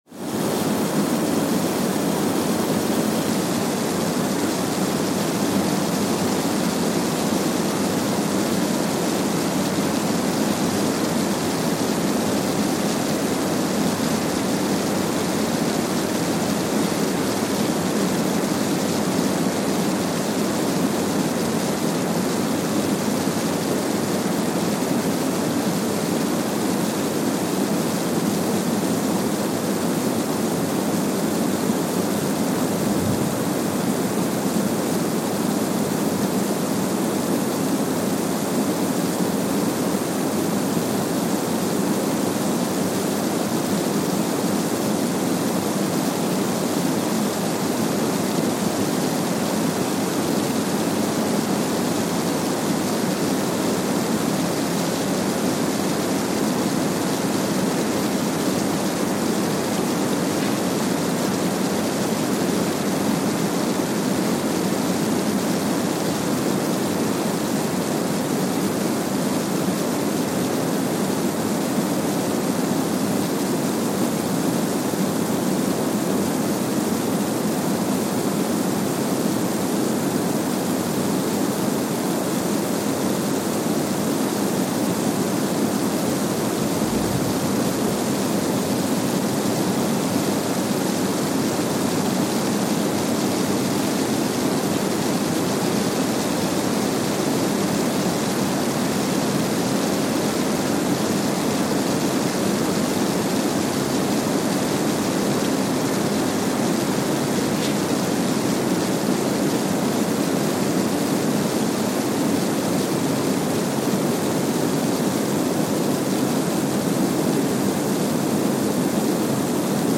Kwajalein Atoll, Marshall Islands (seismic) archived on December 2, 2020
Station : KWJN (network: IRIS/IDA) at Kwajalein Atoll, Marshall Islands
Sensor : Streckeisen STS-5A Seismometer
Speedup : ×1,000 (transposed up about 10 octaves)
Loop duration (audio) : 05:45 (stereo)